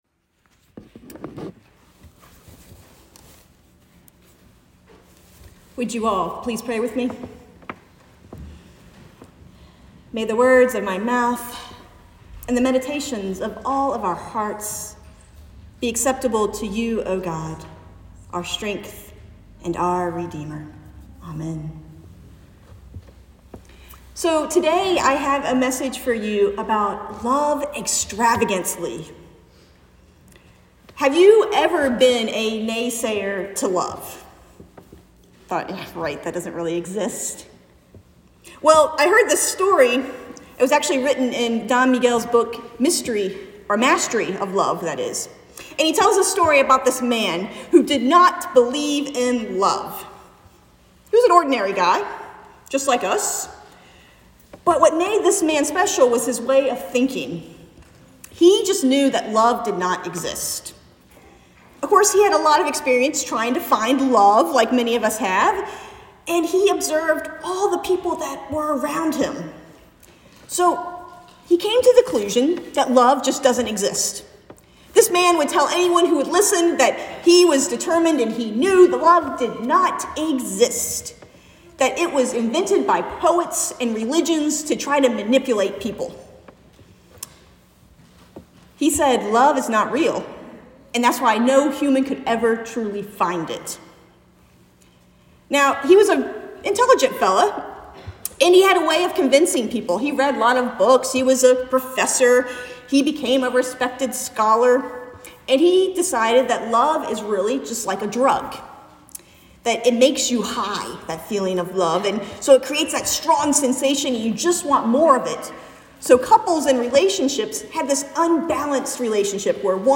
Sermon from February 25, 2023